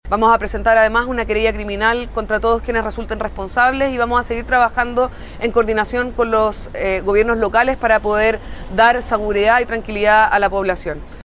La delegada presidencial Metropolitana, Constanza Martínez, anunció la presentación de acciones legales en contra de los responsables.